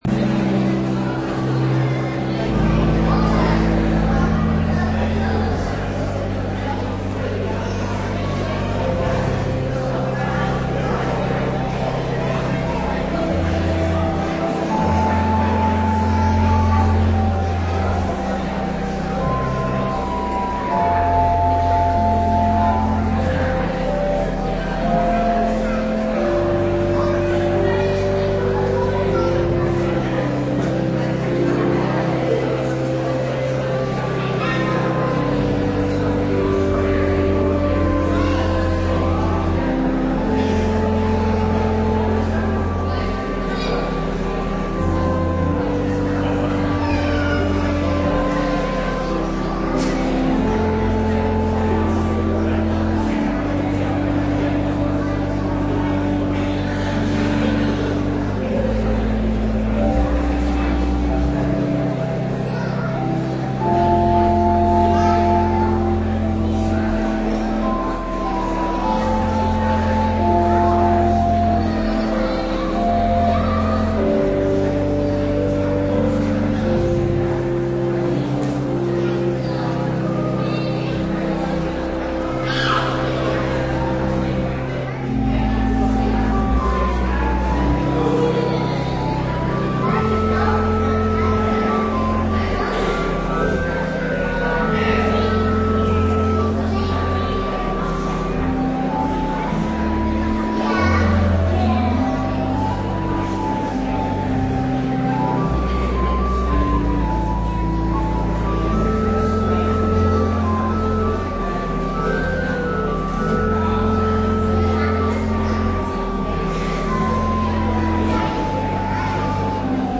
Meditatie over Marcus 14:26-31; 46-52 op Witte Donderdag 1 april 2021 (vesperdienst) - Pauluskerk Gouda